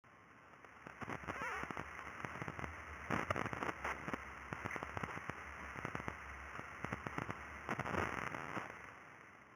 Repeating Blips
¶ Repeating Blips Description: Repeating blips have a morphology similar to blips, but they repeat on regular intervals. They usually repeat every 0.25 or 0.5 seconds, but sometimes repeat with other cadences. Cause: On one occasion in March 2017 during the second observing run (O2), Repeating Blips were caused by a malfunction of the 45 MHz modulation subsystem.